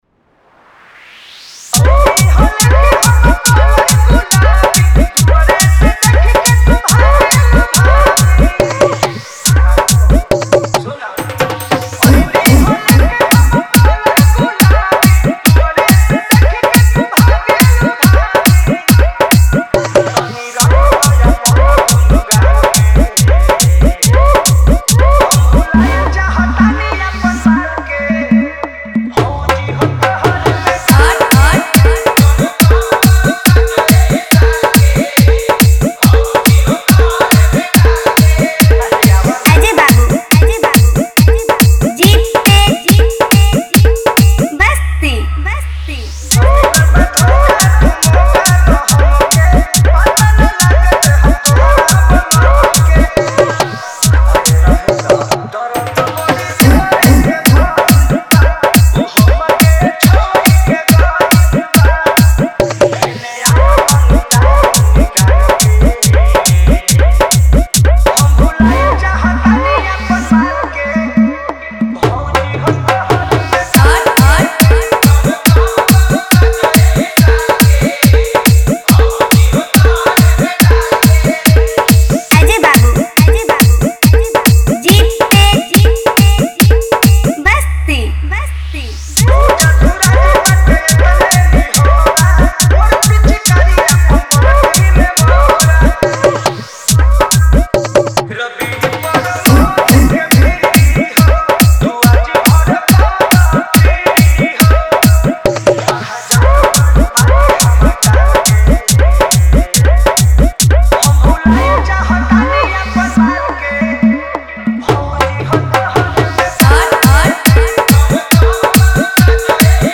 Category : Holi 2025 Wala Dj Remix